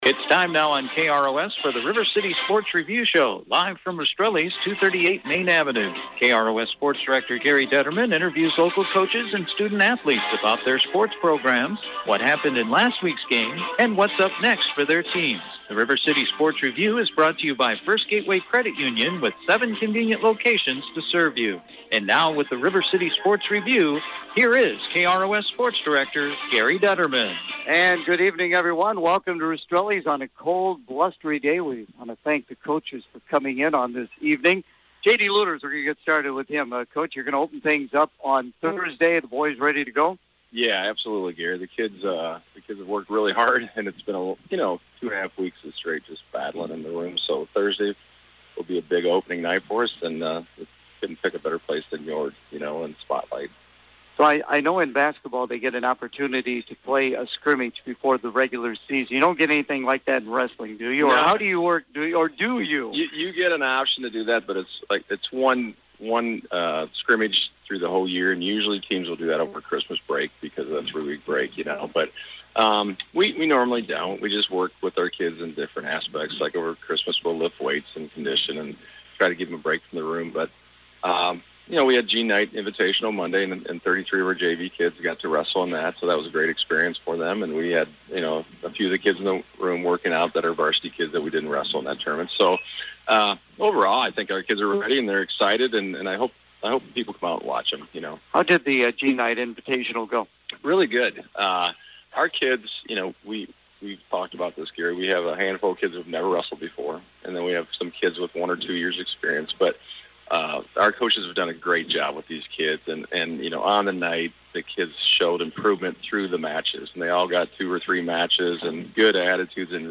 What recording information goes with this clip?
The River City Sports Review Show on Wednesday night from Rastrelli’s Restaurant